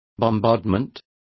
Complete with pronunciation of the translation of bombardment.